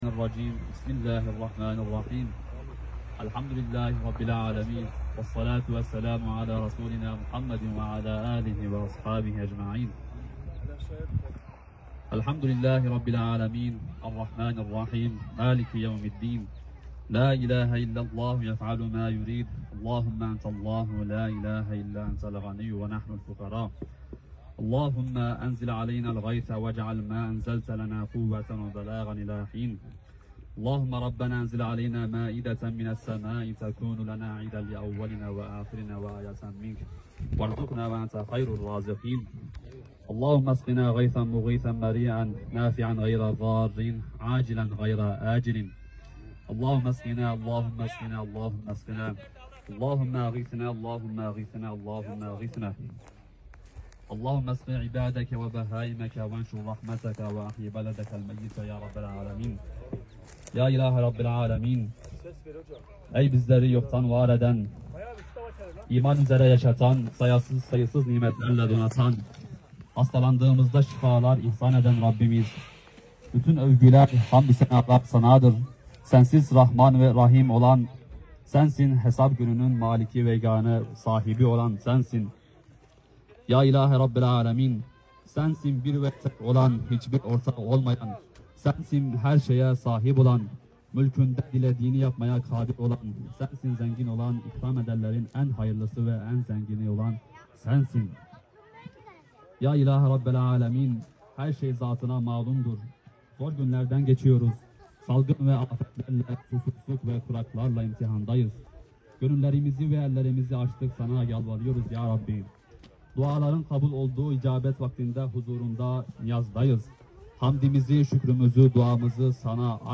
Darende yeşiltaş köyünde yağmur duasına sound effects free download